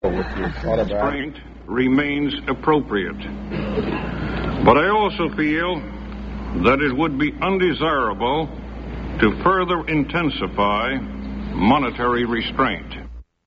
Arthur Burns states that it would be undesirable to further intensify monetary restraint
Broadcast on CBS-TV, September 20, 1974.